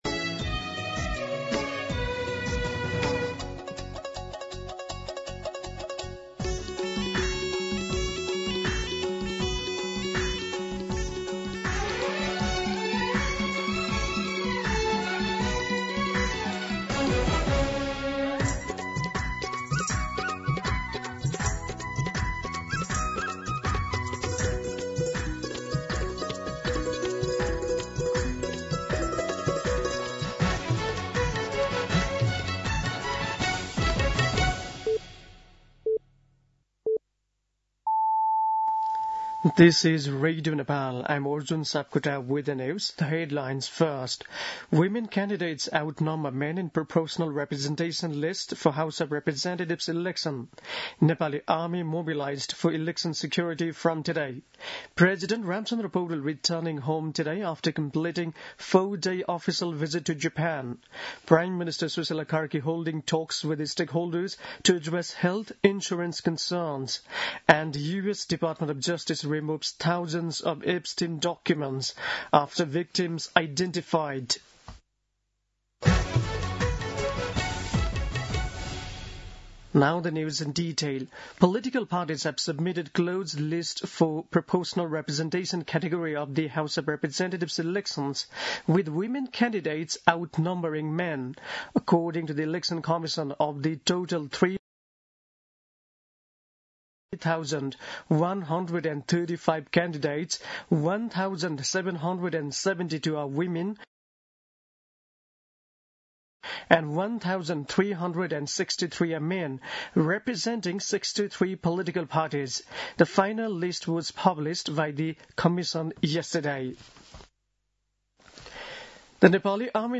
दिउँसो २ बजेको अङ्ग्रेजी समाचार : २१ माघ , २०८२
2-pm-English-News.mp3